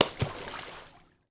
SPLASH_Small_06_mono.wav